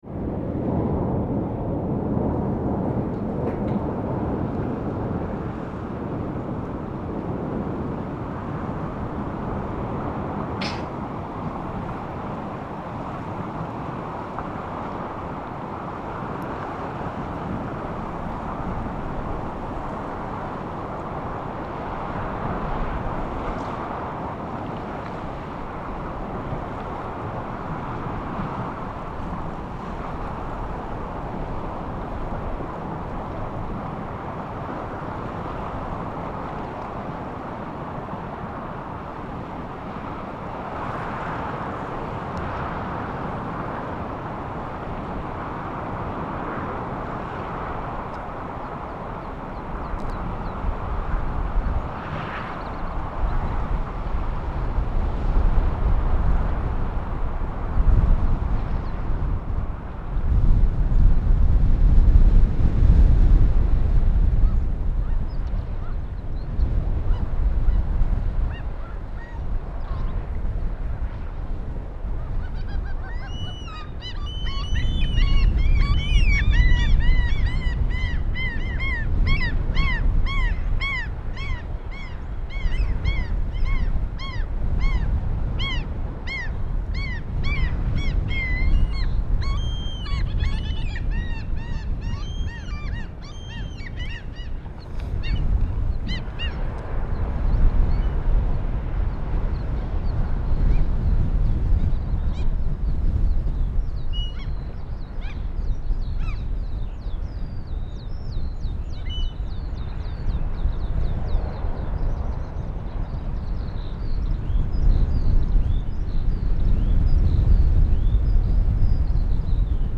The wind coming in off the Atlantic was ferocious.
The opening sound in this track comes from within the barn where the wind batters against the corrugated roof. The final section of this track features a recording taken from cliffs near a cottage some of the crew were living in.
This recording isn’t perfect; a couple of foot shuffles and microphone windshield noise can be heard, however this adds to the overall rawness of the recording and takes me straight back to standing on those rocks with the wind and spray in my face.
3.howl.mp3